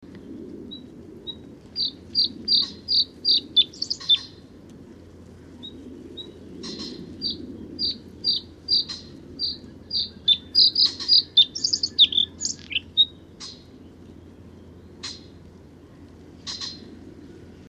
Här nedan hittar du en lista med fågelsång och fågelläten av mer än 400 fågelarter.
Blåhake Blue Throat Luscinia svecica